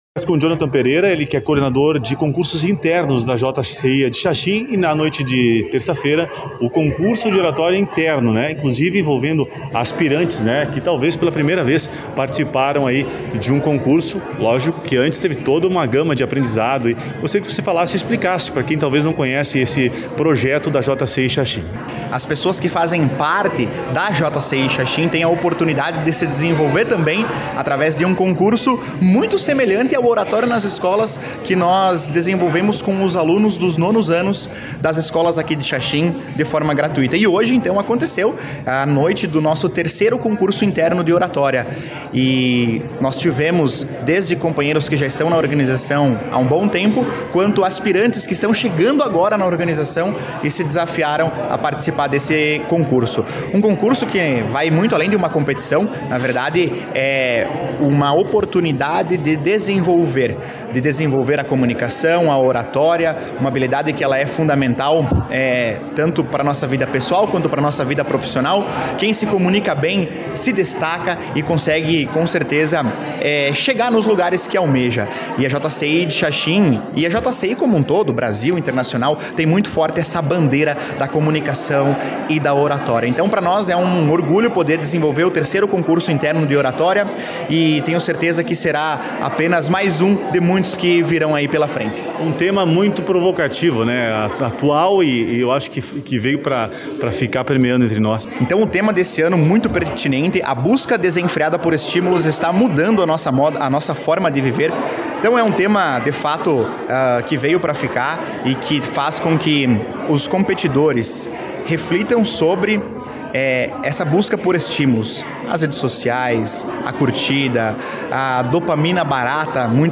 A fase municipal ocorreu na noite de terça-feira (06) com início as 19h30 na Câmara de Vereadores